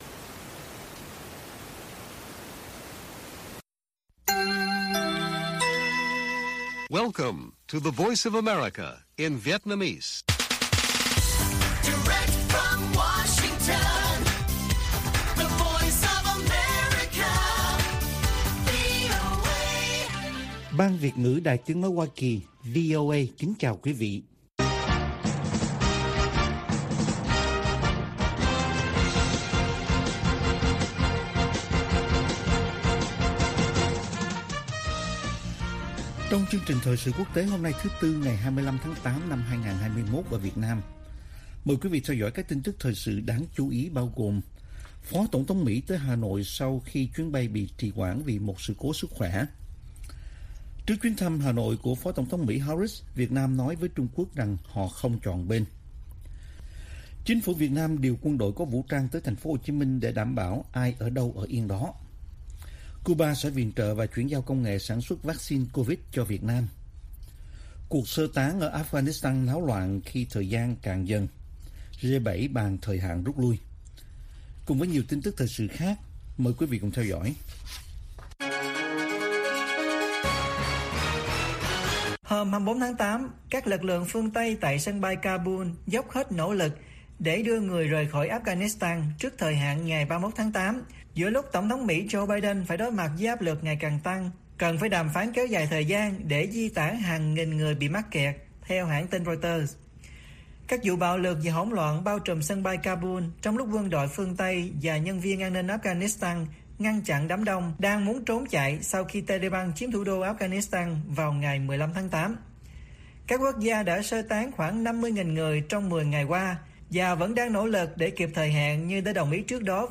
Bản tin VOA ngày 25/8/2021